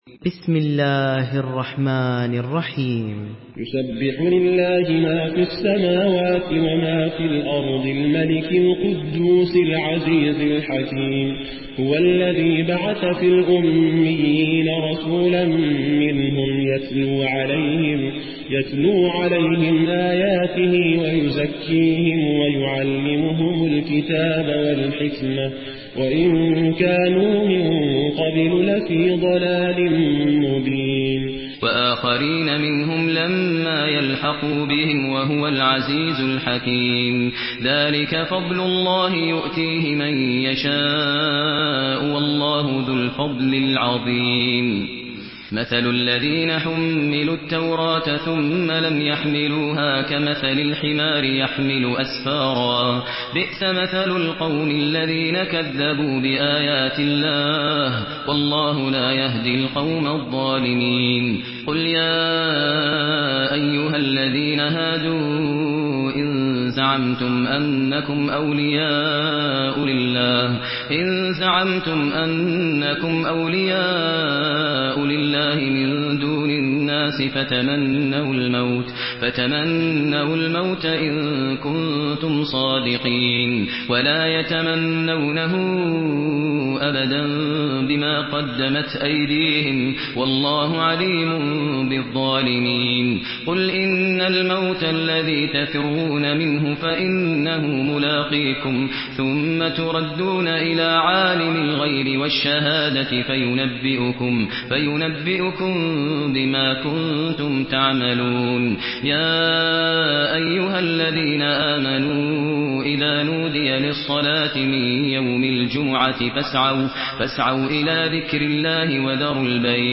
Surah Cüma MP3 by Maher Al Muaiqly in Hafs An Asim narration.
Murattal Hafs An Asim